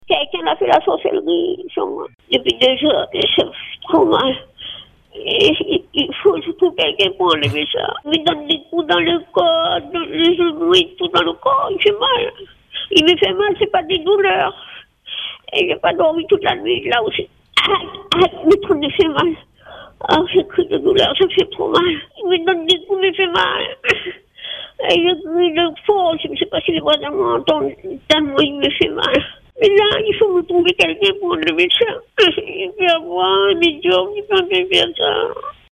Elle le dit sans détour sur notre antenne : elle se pense victime de sorcellerie.